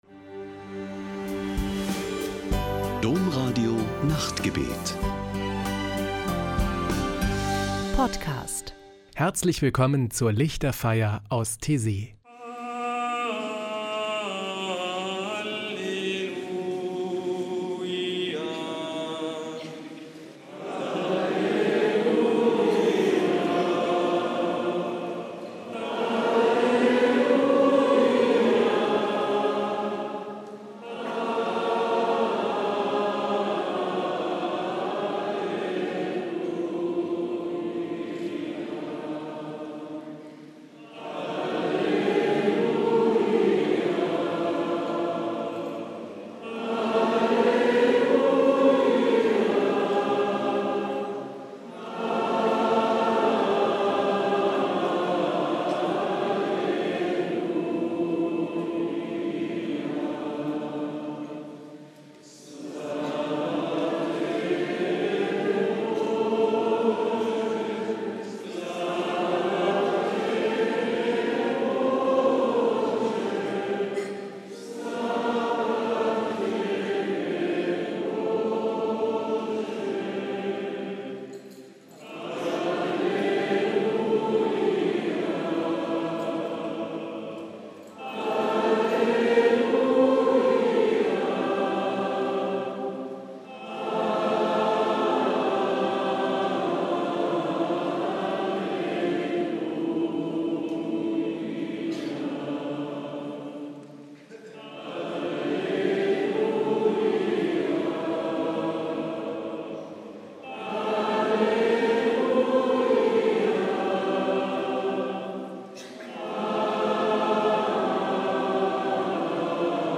Die Lichterfeier aus Taizé: Spirituelle Gesänge und Gebete
Taizé, ein kleiner Ort im französischen Burgund, steht für Spiritualität und Ökumene.
Ein Höhepunkt jede Woche ist am Samstagabend die Lichterfeier mit meditativen Gesängen und Gebeten.